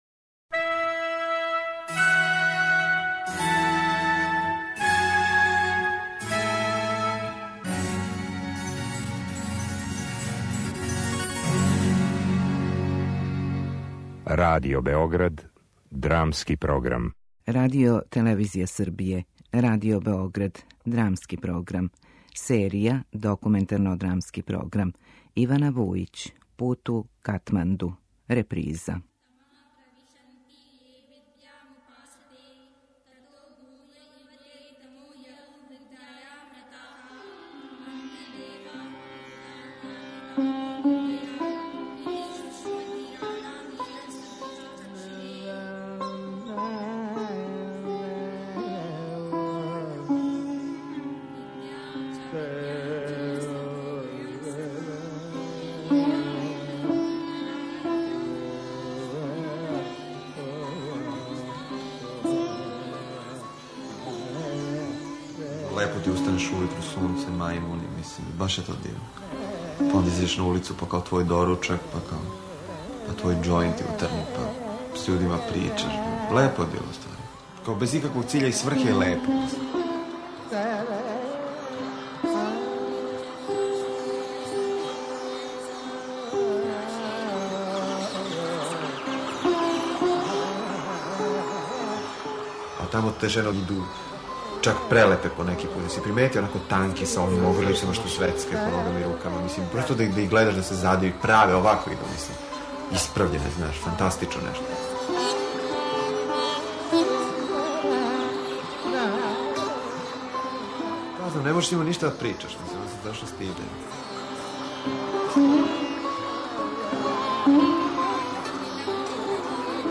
Документарно-драмски програм
Документарни материјал ове радио-драме снимљен је у београдском Централном затвору марта 1982. године. Коришћени су и текстови Самјуела Бекета и Ежена Јонеска.